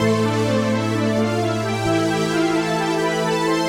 AM_VictorPad_130-A.wav